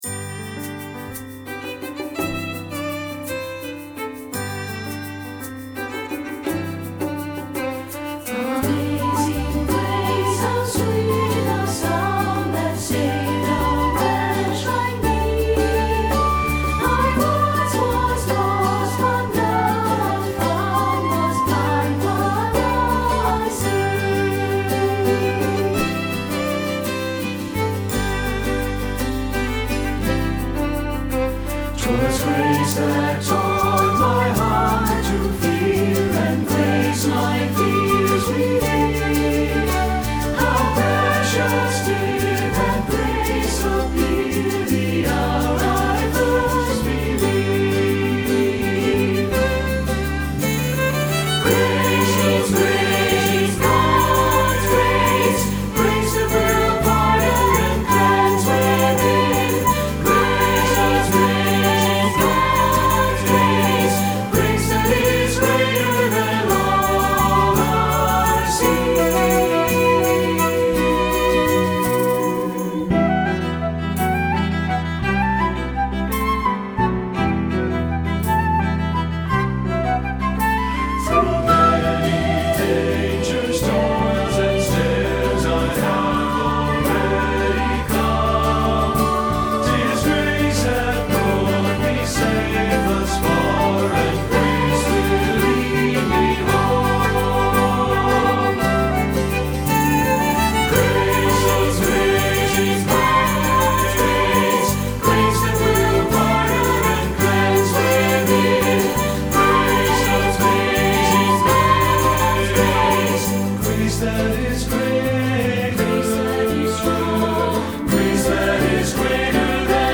Feuillet pour Chant/vocal/choeur - SAB